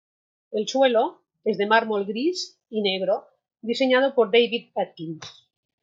Pronounced as (IPA) /daˈbid/